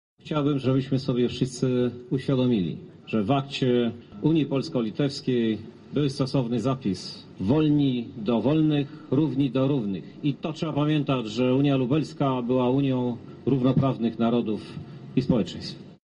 W uroczystości odsłonięcia tablicy wzięli udział prezydenci Wilna, Poniewieża oraz włodarz Lublina Krzysztof Żuk, który zabrał głos: